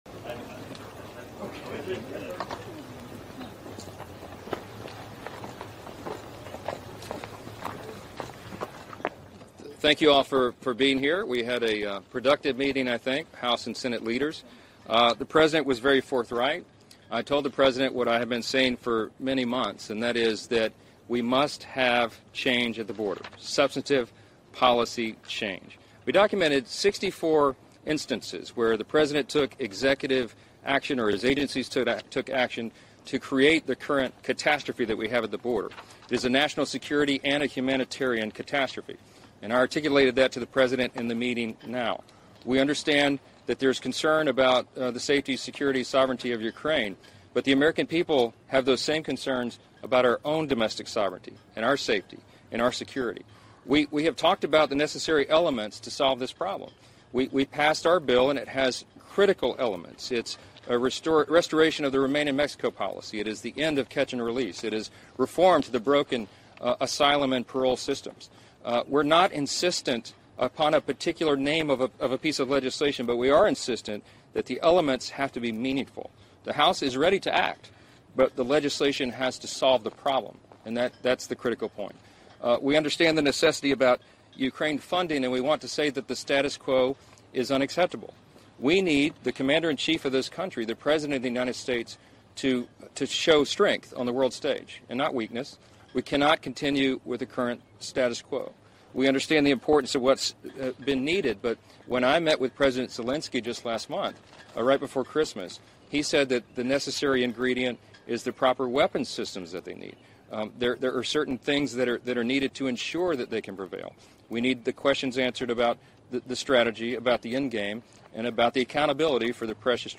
Youtube title: BREAKING NEWS: Speaker Johnson Speaks To Reporters After White House Meeting With Biden, Schumer